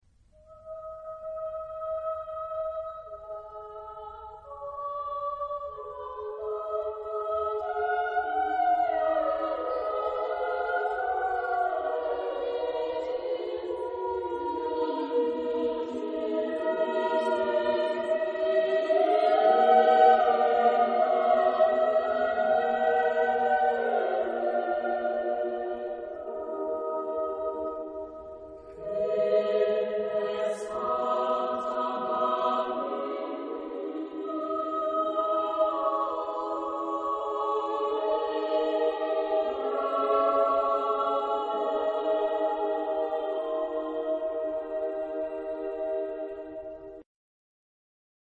Tipo de formación coral: SSAT  (4 voces Coro mixto )